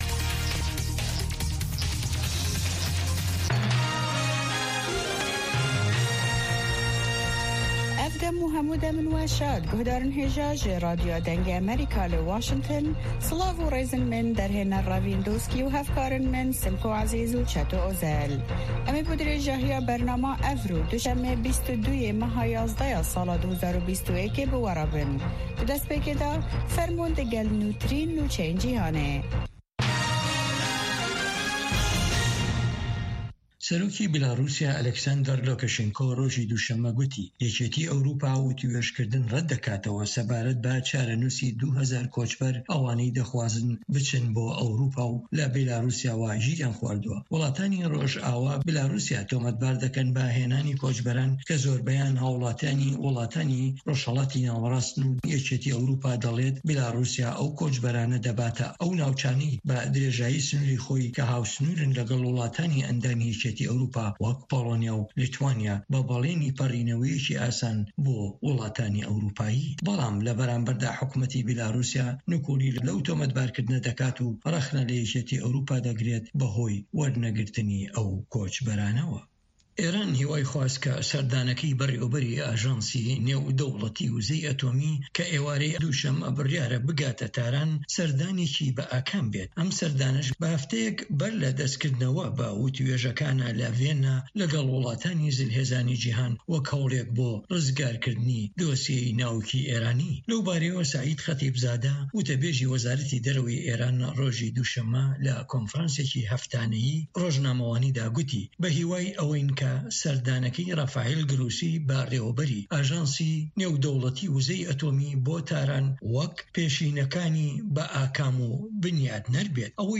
هەواڵەکانی 1 ی پاش نیوەڕۆ